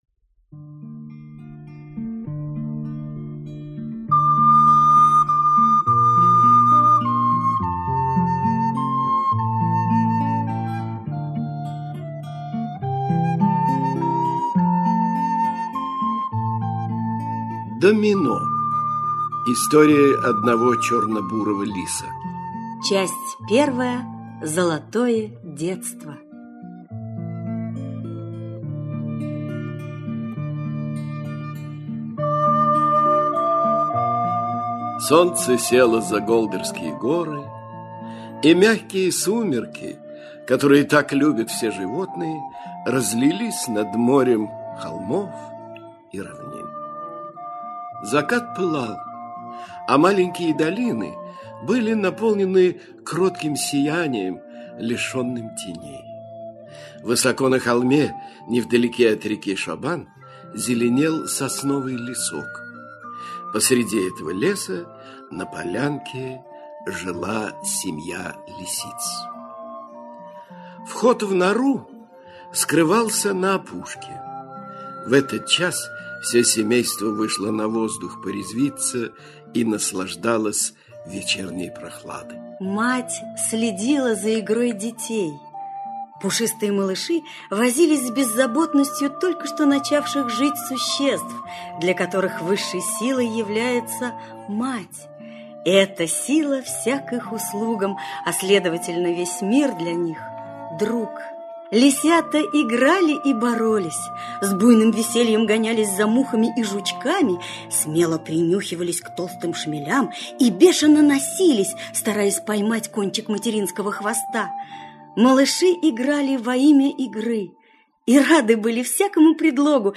Домино - аудио рассказ Эрнеста Сетона-Томпсона - слушать онлайн